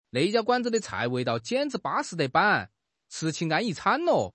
描述：一个跳脱市井的四川成都男子。
支持的语种/方言：中文（四川话）